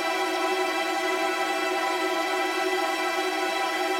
GS_TremString-Emin9.wav